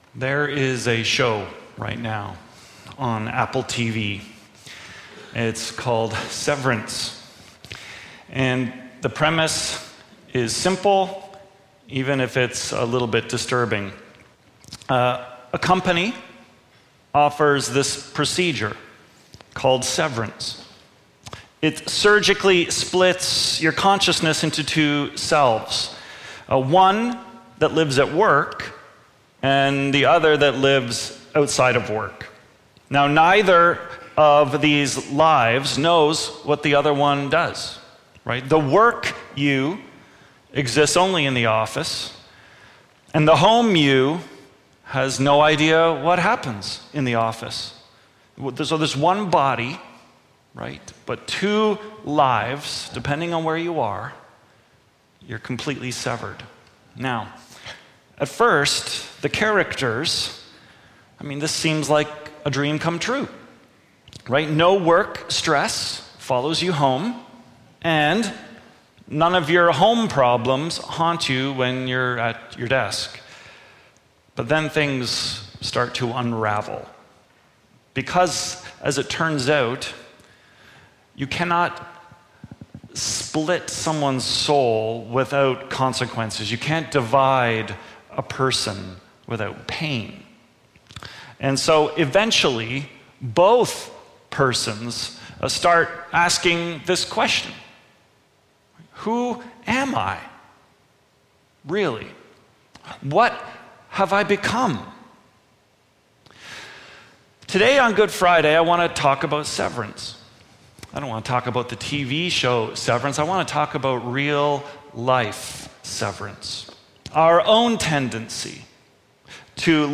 Trinity Streetsville - Severance | Good Friday Sermon 2025